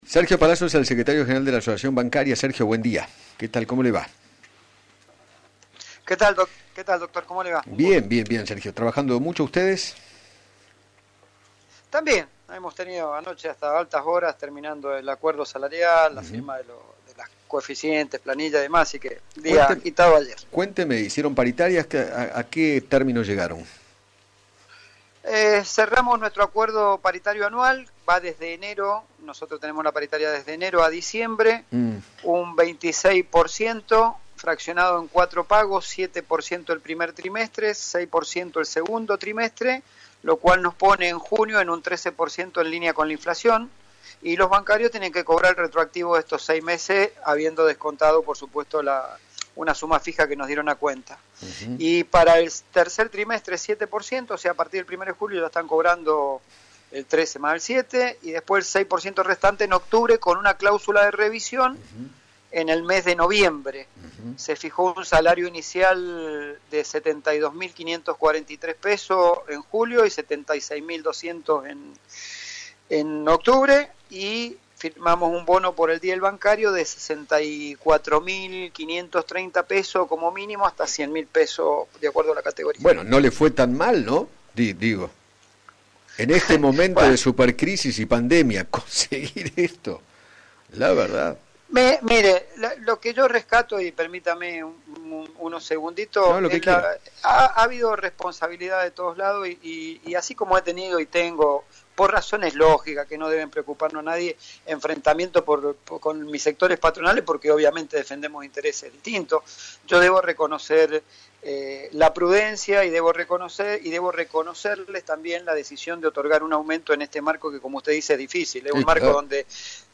Sergio Palazzo, Secretario General de la Asociación Bancaria, dialogó con Eduardo Feinmann sobre el acuerdo paritario que cerró ayer con las cámaras empresariales del sistema financiero, en el que, se pactó un aumento salarial del 26 por ciento para este año sobre los salarios de diciembre último.